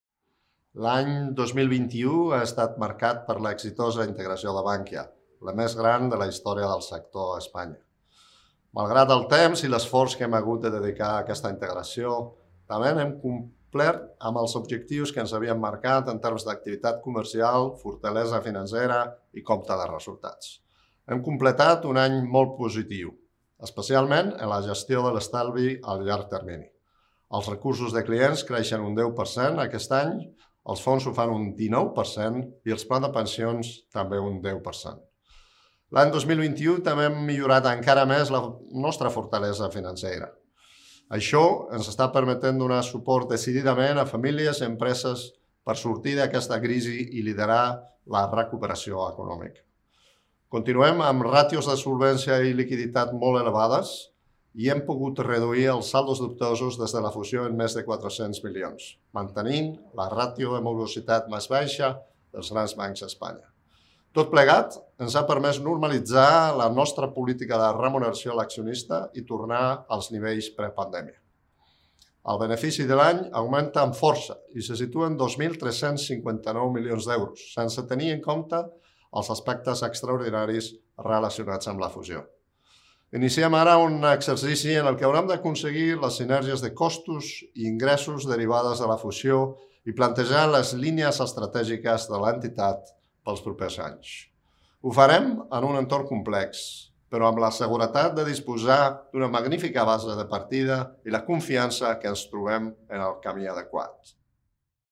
Àudio del CEO de CaixaBank, Gonzalo Gortázar